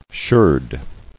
[sherd]